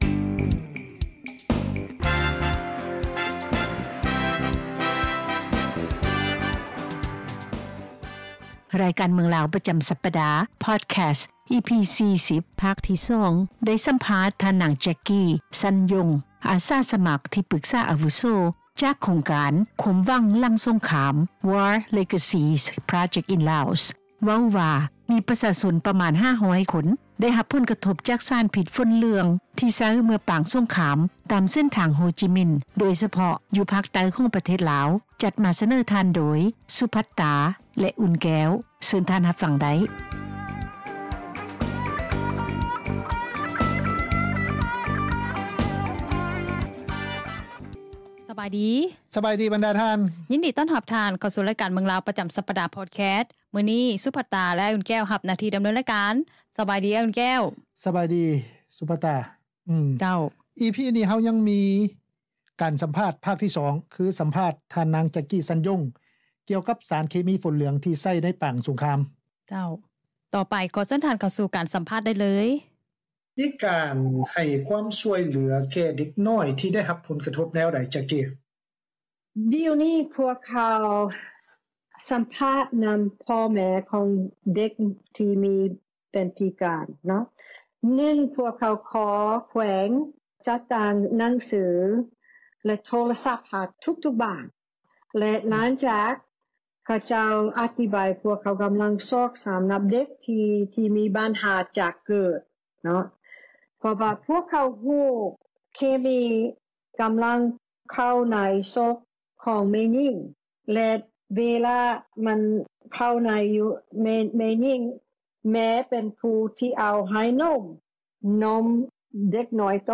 EP-40 ພາກທີ 2 ໄດ້ສຳພາດ